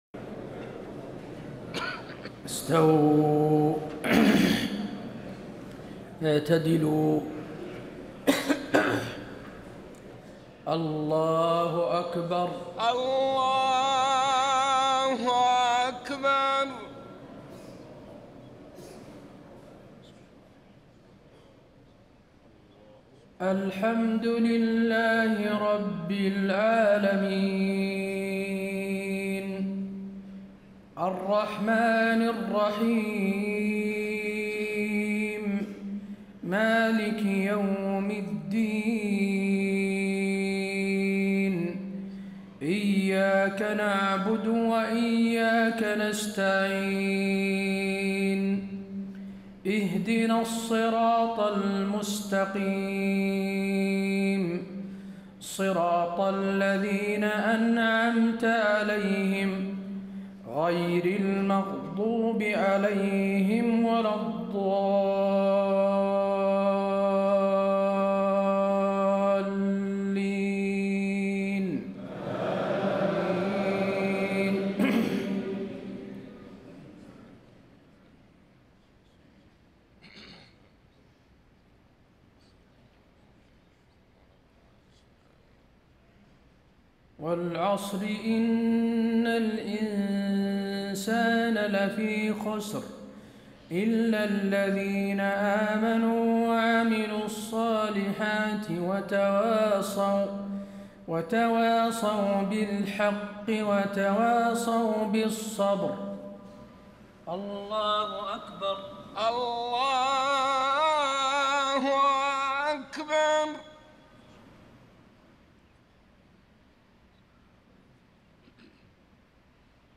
صلاة المغرب 4-9-1434 سورتي العصر و النصر > 1434 🕌 > الفروض - تلاوات الحرمين